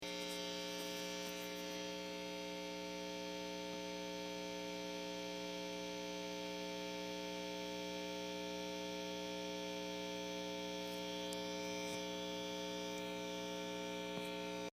I plugged in my lovely Strat and turned up the Normal vol but I get zilch...just hum.
Sounds like 60hz to me.
It sounds like a cable with no guitar connected.
hum.mp3